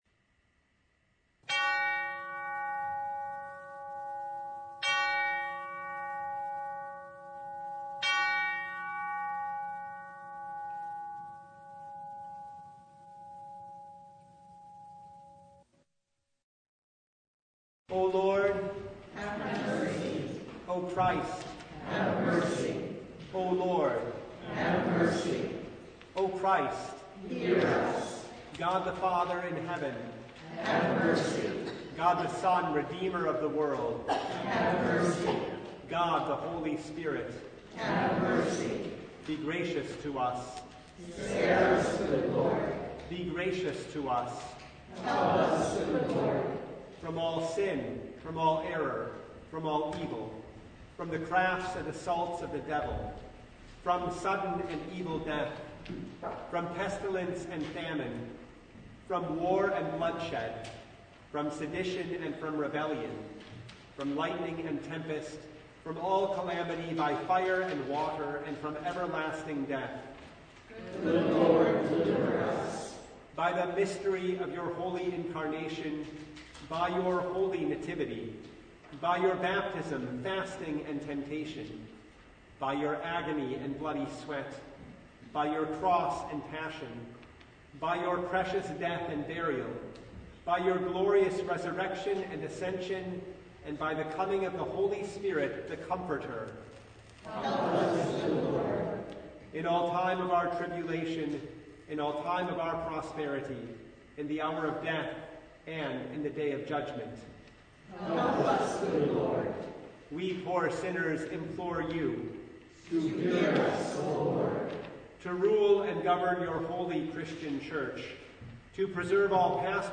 Passage: Mark 7:24-30 Service Type: Lent Midweek Noon
Full Service